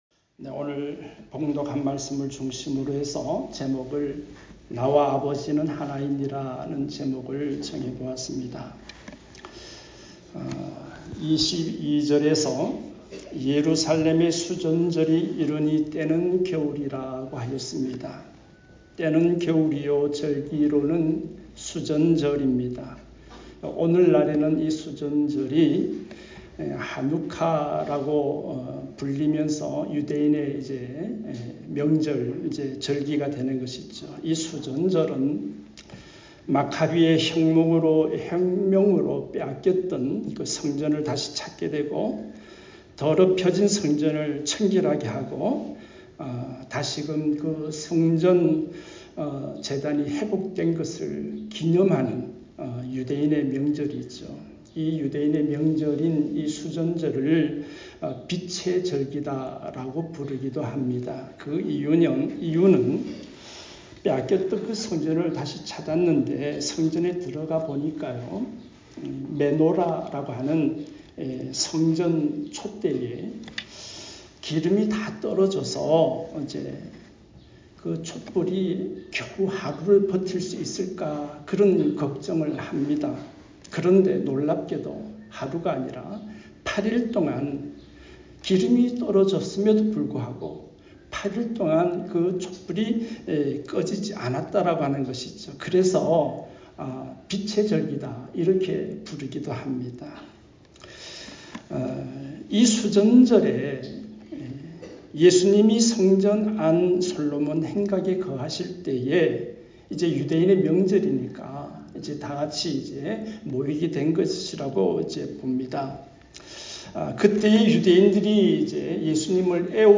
주일음성설교 에 포함되어 있습니다.